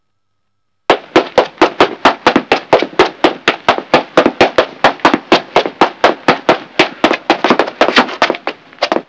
Running Feet Sound Effect Free Download
Running Feet